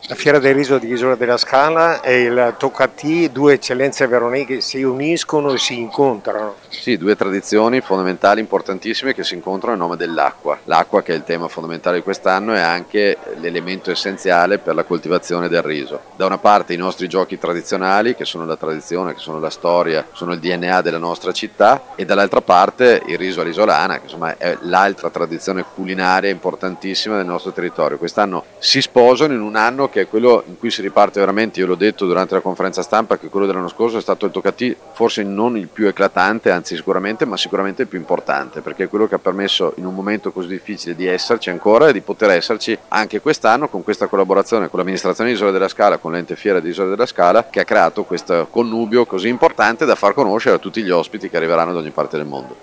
Il Sindaco di Verona Federico Sboarina: